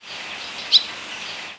Barn Swallow Hirundo rustica
Flight call description A sweet, slightly burry, rising "vwit", often doubled, and a two-syllabled, whistled "psewi".
Fig.1. New Jersey August 5, 2001 (MO).
"Vwit" call from bird in flight.